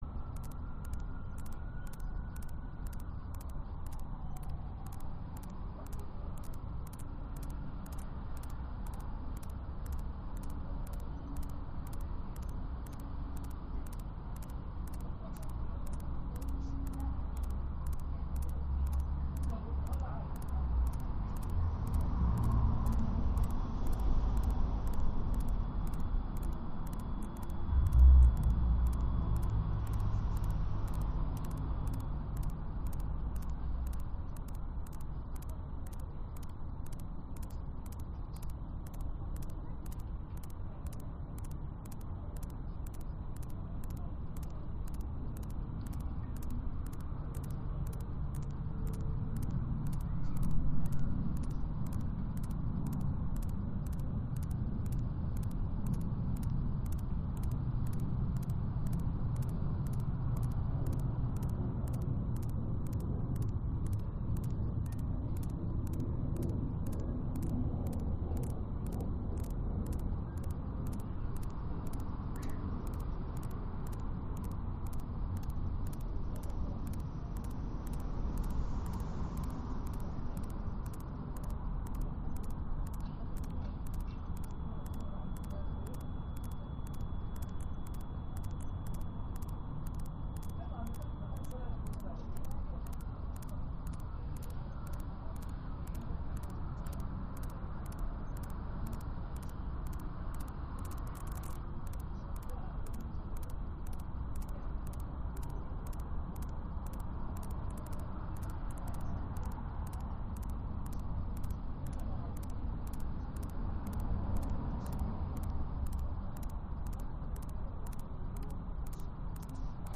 Live from Soundcamp: La Escocesa mixing in Barcelona (Audio) Sep 13, 2025 shows Live from Soundcamp A listening/mixing station will combine live streams of back yards in Barcelona and beyond Play In New Tab (audio/mpeg) Download (audio/mpeg)